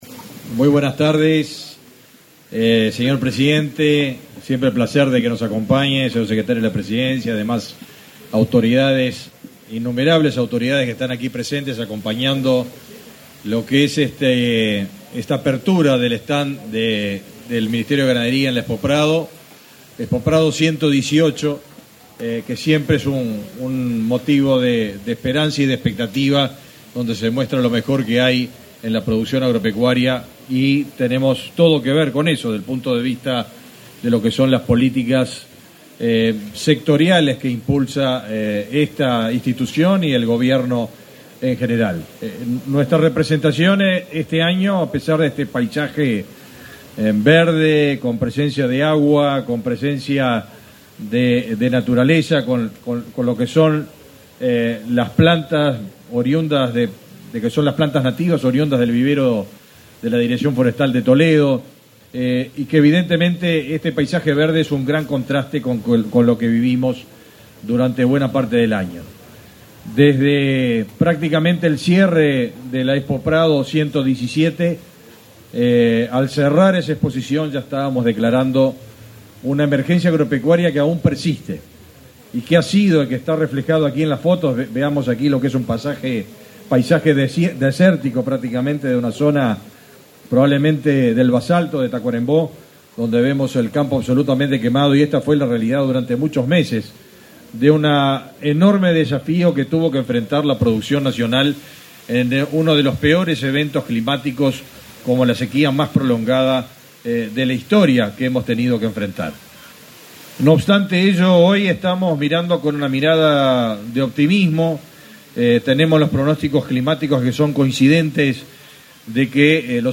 Palabras del ministro de Ganadería, Fernando Mattos
Palabras del ministro de Ganadería, Fernando Mattos 08/09/2023 Compartir Facebook X Copiar enlace WhatsApp LinkedIn En el marco de la apertura del stand del Ministerio de Ganadería, Agricultura y Pesca (MGAP) en la Expo Prado 2023, las autoridades ministeriales realizaron una conferencia de prensa sobre las medidas tomadas durante la crisis hídrica. El ministro Fernando Mattos disertó al respecto.